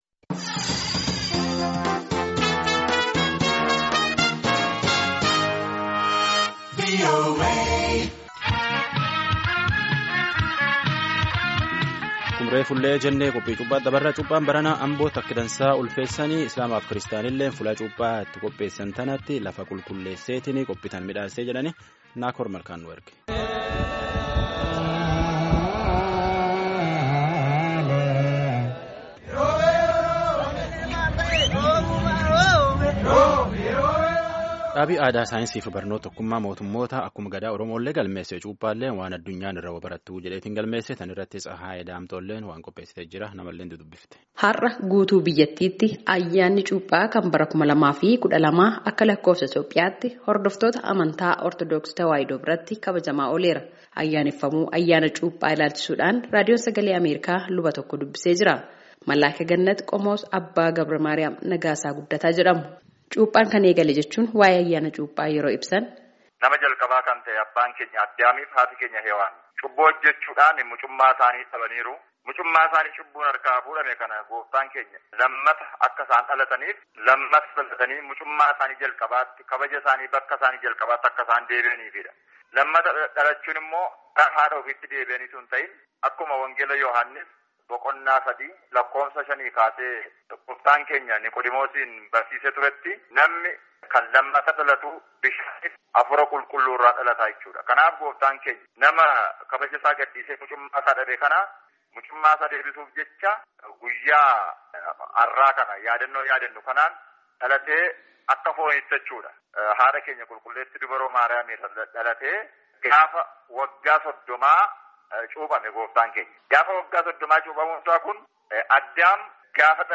Maaliif guyyaan kun akka waggaa waggaatti ayyaaneffamuu fi sirna bara kana aka baroota dabranii maal akka adda godhu, luba Waldaa Kiristaanaaa Ortodoksii tokko dubbisnee jirra.